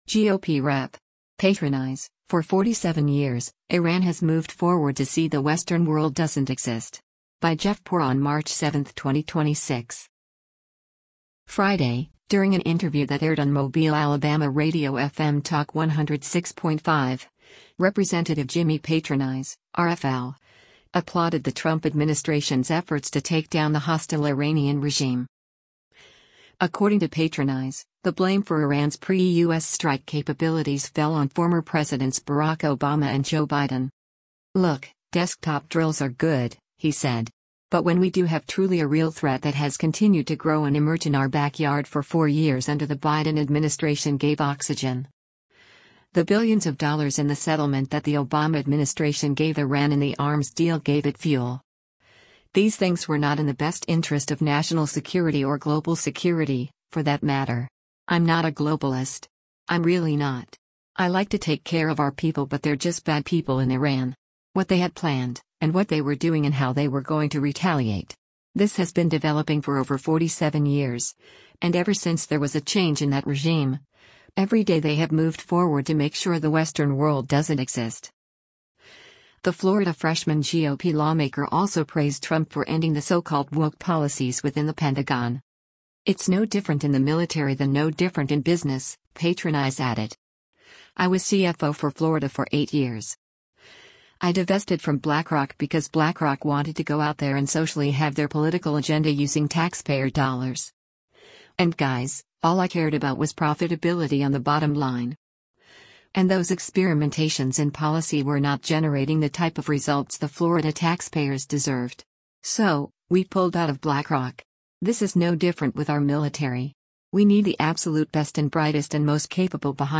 Friday, during an interview that aired on Mobile, AL radio FM Talk 106.5, Rep. Jimmy Patronis (R-FL) applauded the Trump administration’s efforts to take down the hostile Iranian regime.